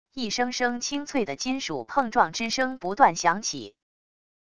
一声声清脆的金属碰撞之声不断响起wav音频